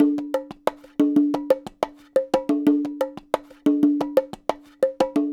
Bongo 15.wav